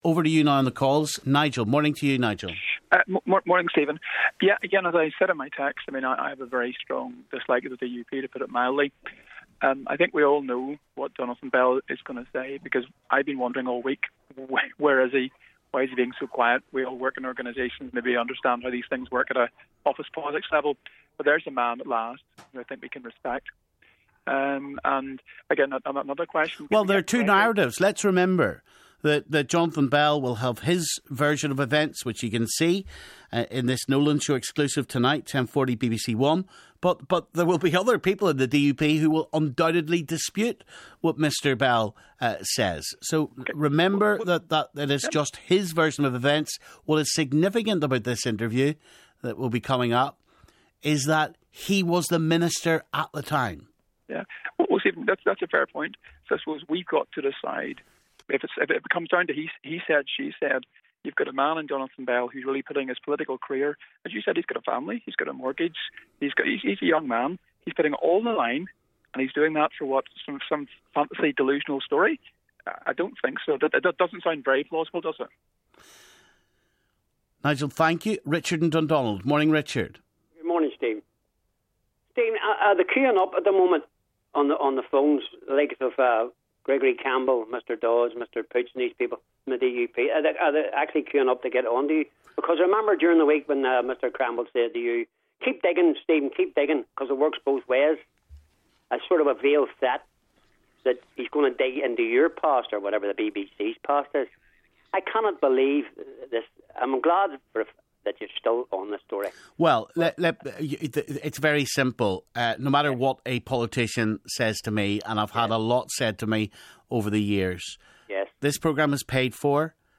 Nolan callers react to latest RHI scandal developments